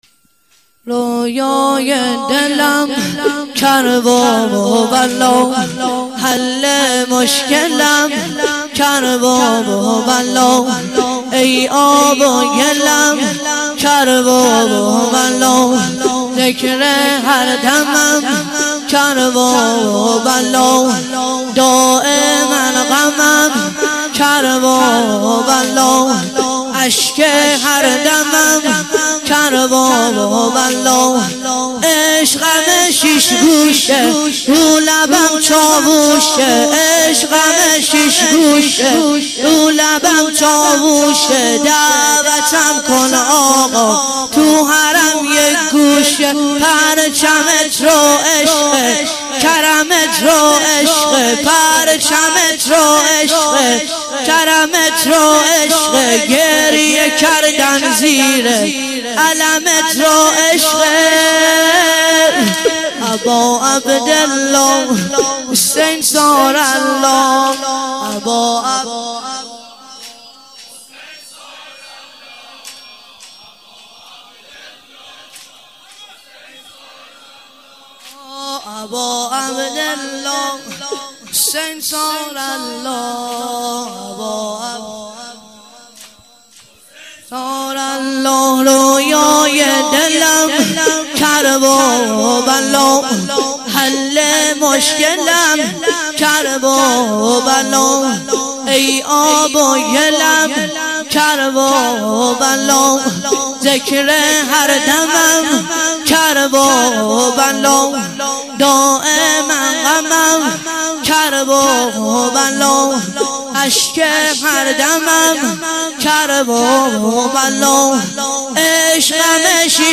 واحد - رویای دلم کرببلا